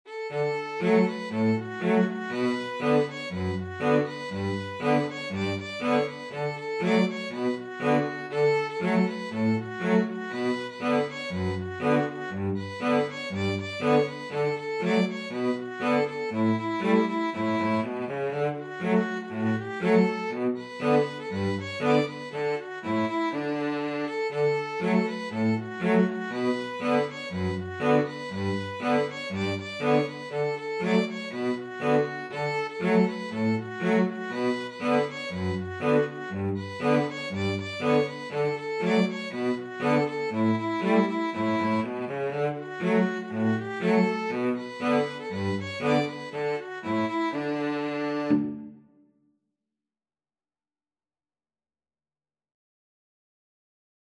Free Sheet music for Violin-Cello Duet
D major (Sounding Pitch) (View more D major Music for Violin-Cello Duet )
2/2 (View more 2/2 Music)
Allegro =c.120 (View more music marked Allegro)
Traditional (View more Traditional Violin-Cello Duet Music)
Irish